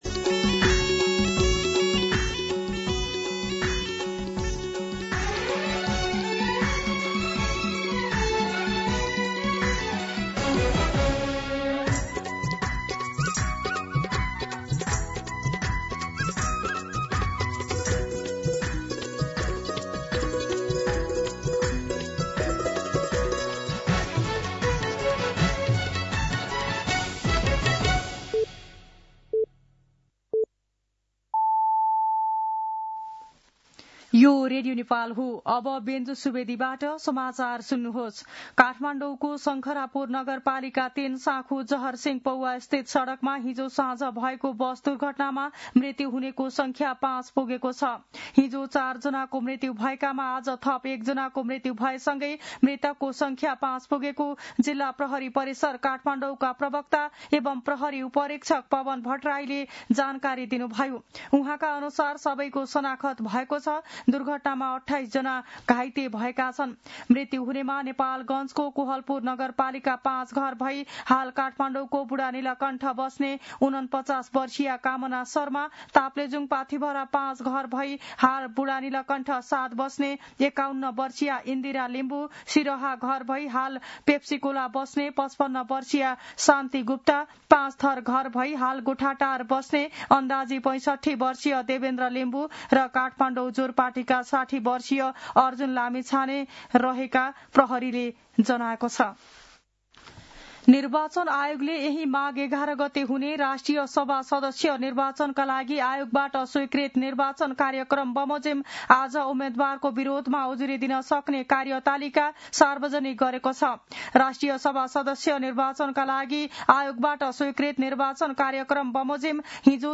मध्यान्ह १२ बजेको नेपाली समाचार : २४ पुष , २०८२